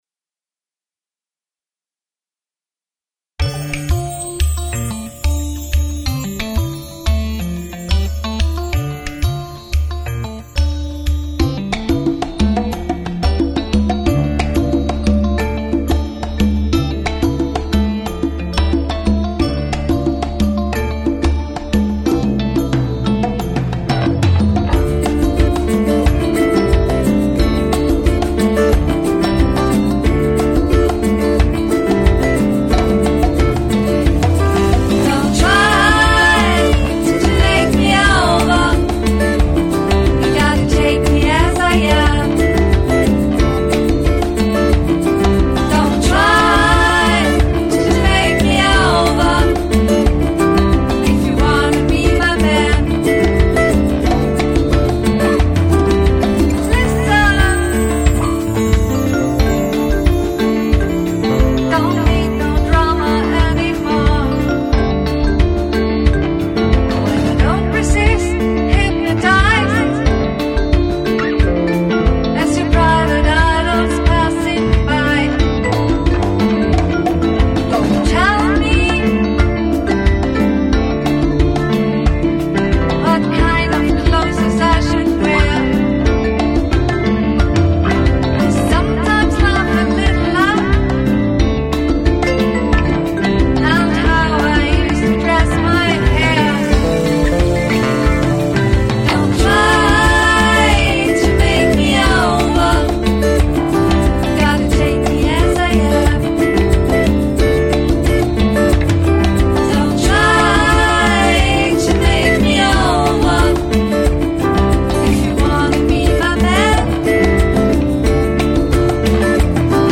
Vocals
Gitarre
Bass